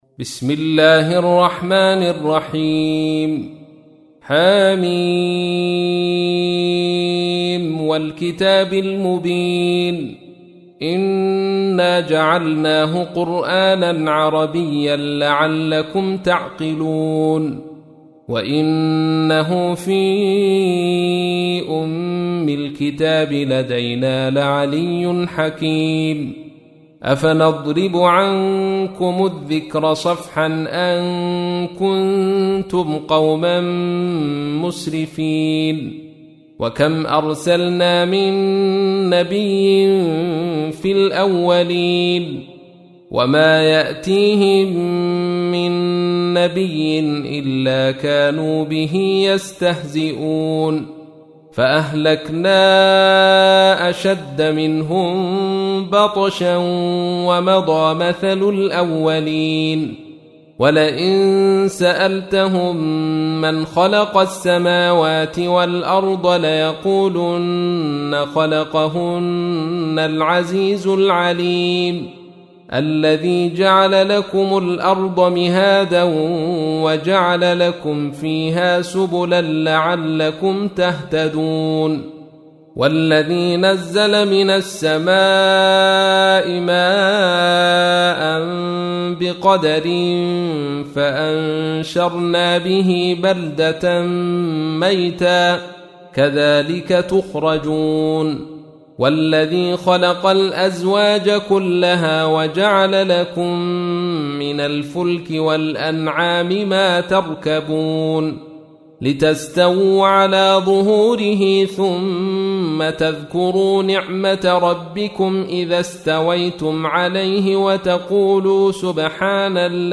43. سورة الزخرف / القارئ